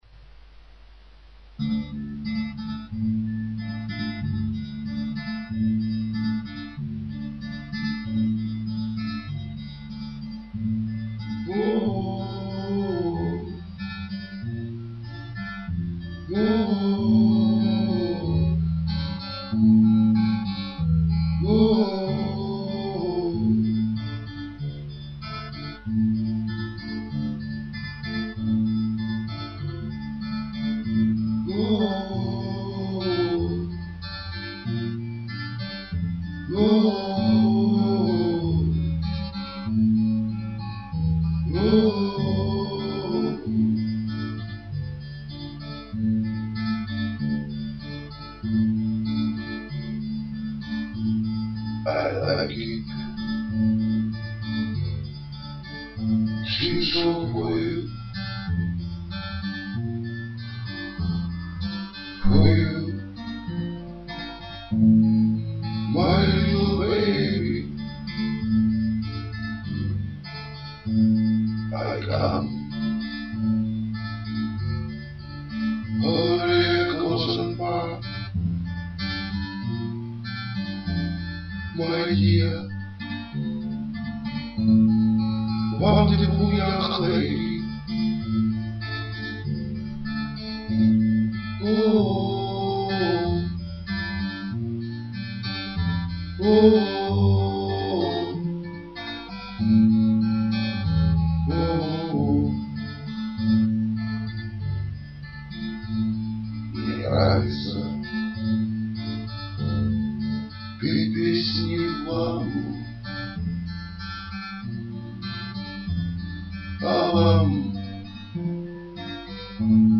• Жанр: Блюз
Ре минор 4/4, блюз,